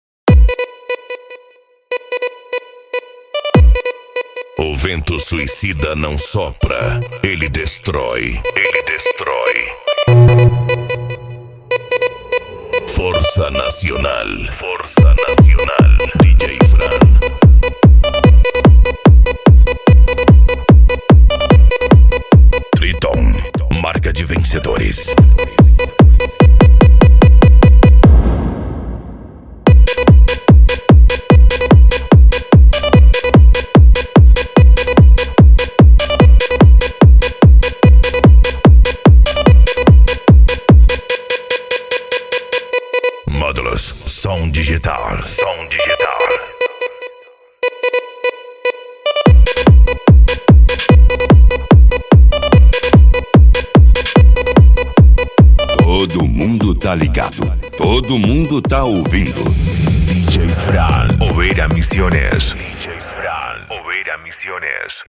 Bass
PANCADÃO
Psy Trance
Racha De Som